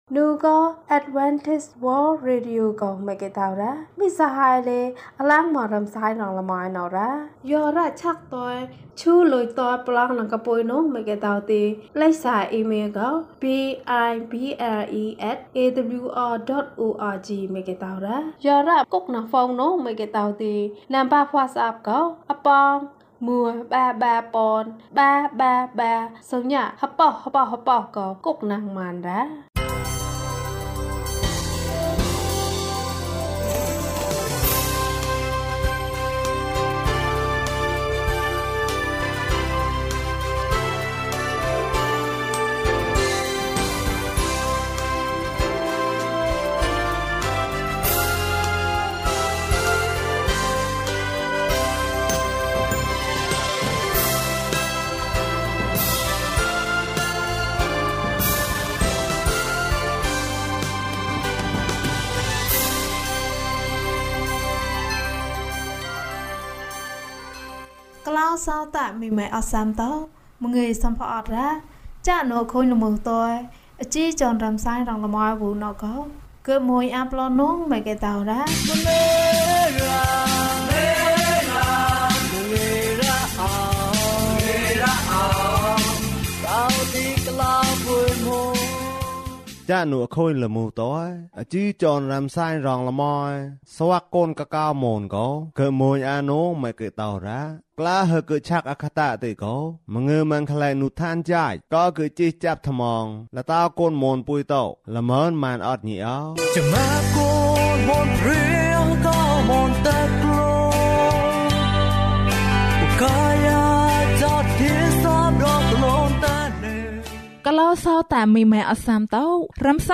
ယေရှုနှင့်အတူ ဆုတောင်းပါ၏။ အပိုင်း ၂ ကျန်းမာခြင်းအကြောင်းအရာ။ ဓမ္မသီချင်း။ တရားဒေသနာ။